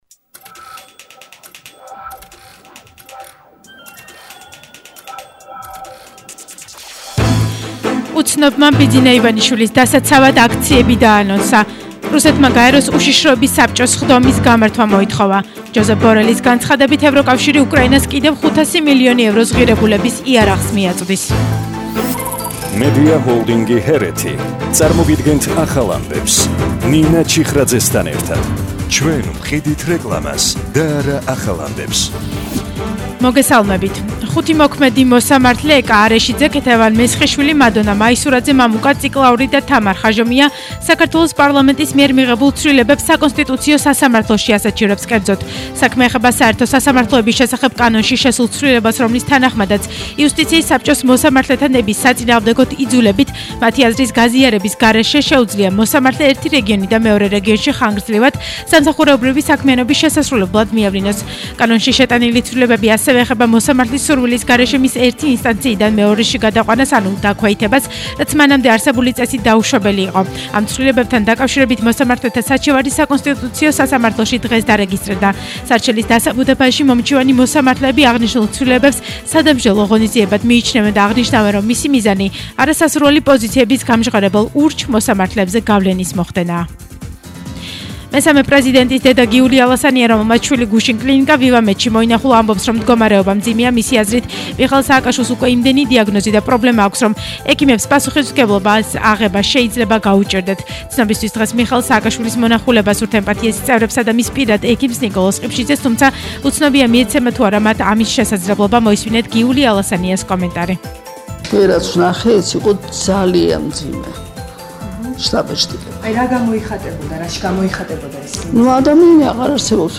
ახალი ამბები 13:00 საათზე – 13/05/22